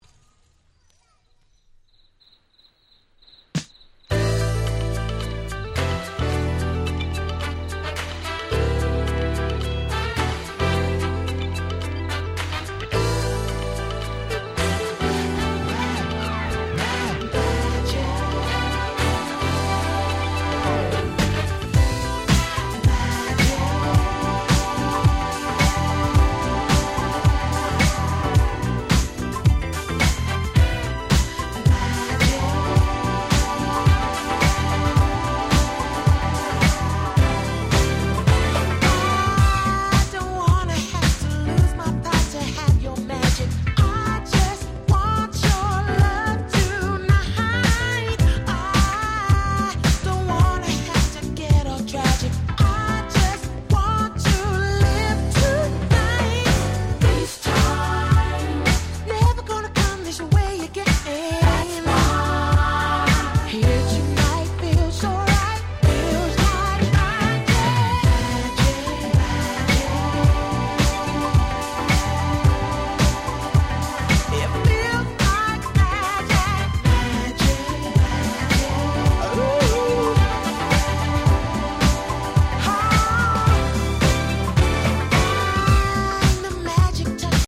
UK R&B Classic LP !!